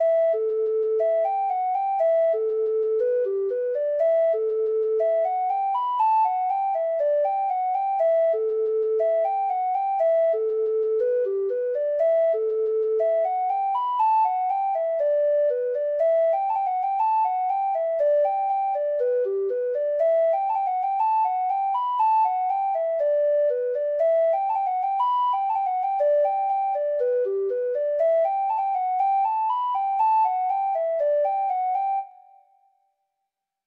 Free Sheet music for Treble Clef Instrument
Reels
Irish